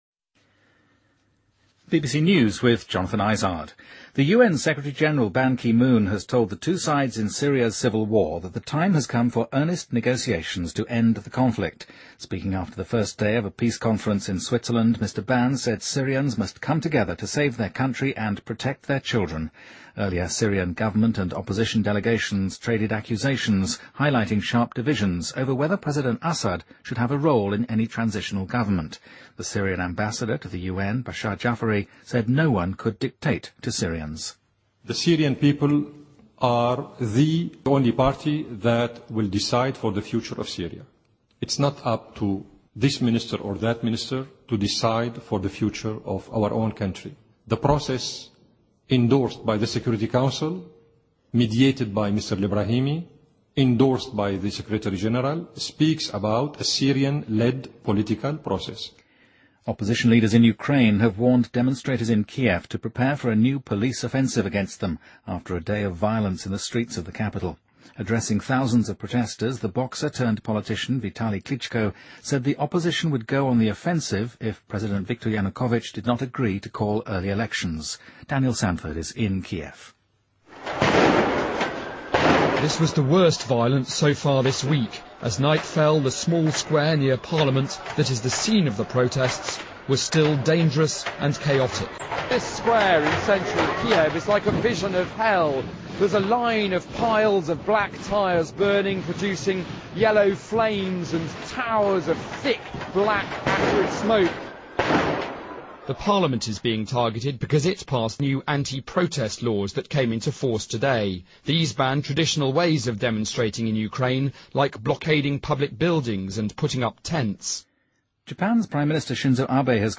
BBC news,2014-01-23